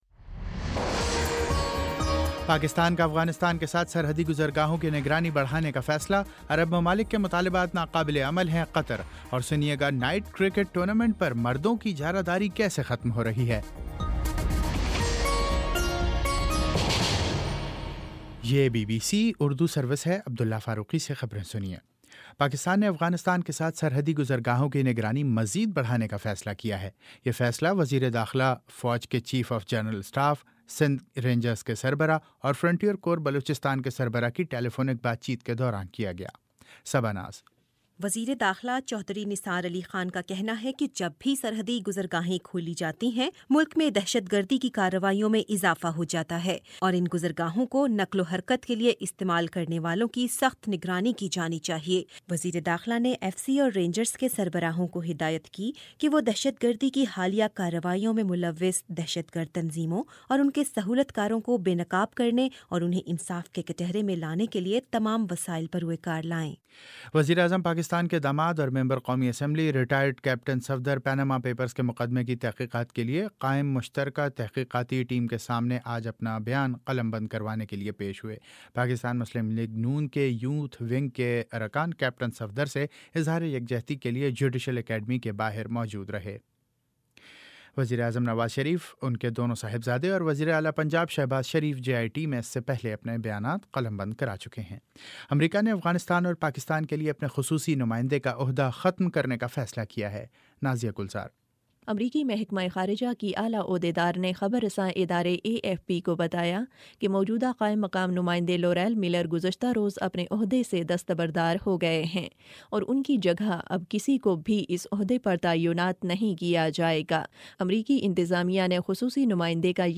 جون 24 : شام پانچ بجے کا نیوز بُلیٹن